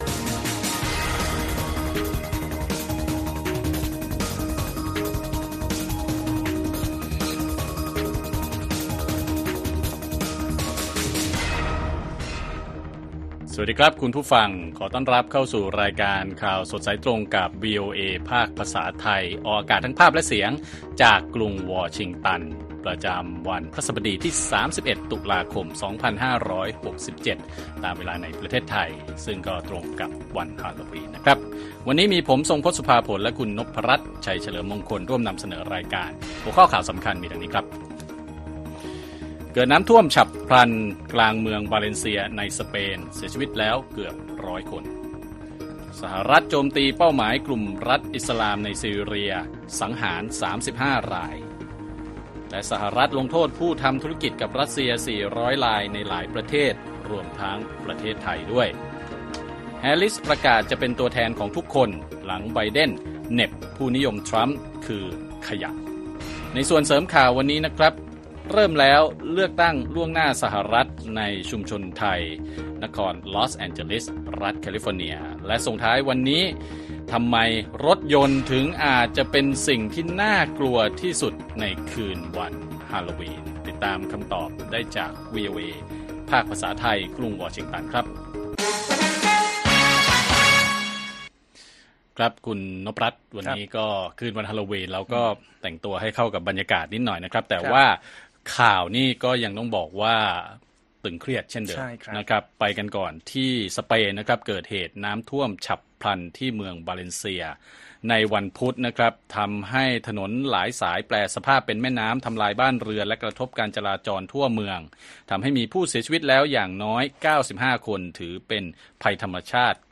ข่าวสดสายตรงจากวีโอเอไทย ประจำวันพฤหัสบดี ที่ 31 ตุลาคม 2567